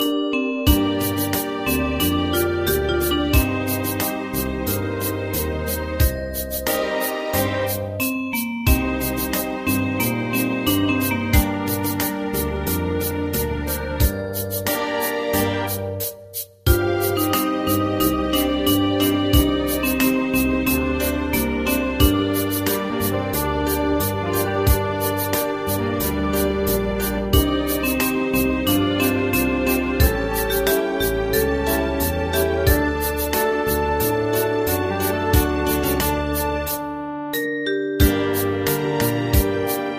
大正琴の「楽譜、練習用の音」データのセットをダウンロードで『すぐに』お届け！
Ensemble musical score and practice for data.
Tags: Japanese , Kayokyoku Enka .